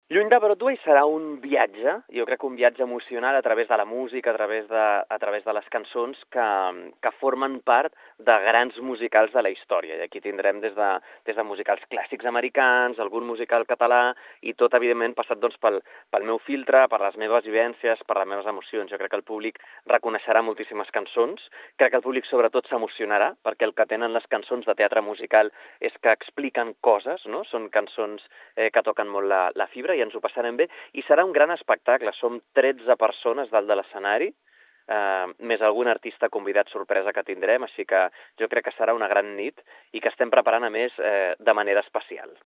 Daniel Anglès, cantant